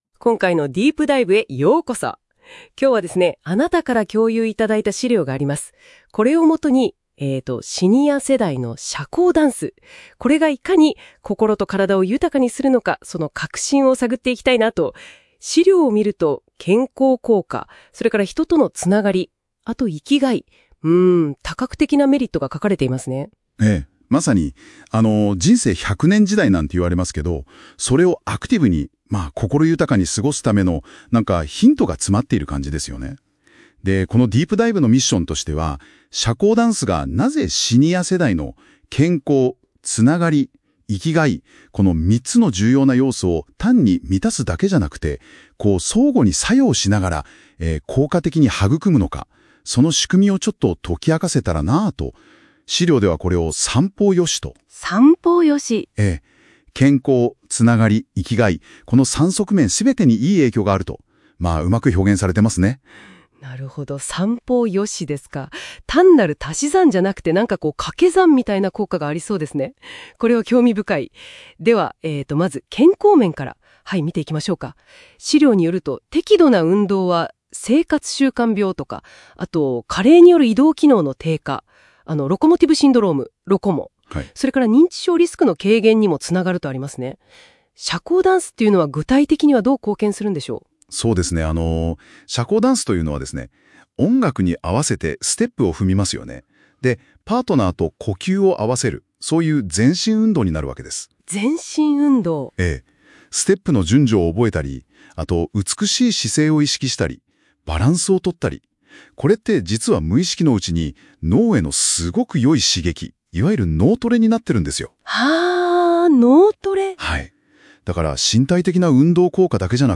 このブログについて、AIで生成した二人で話す会話音声（約7分）も、お楽しみいただけます。